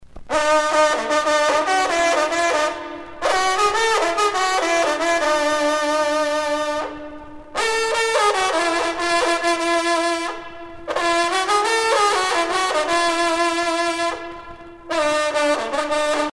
circonstance : vénerie
Pièce musicale éditée